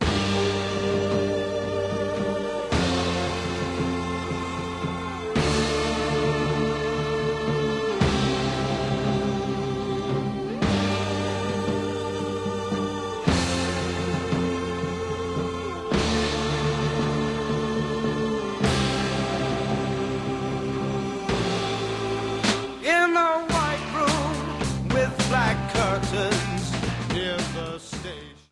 Жанрблюз-рок[1]
психоделічний рок[2]
хардрок[4]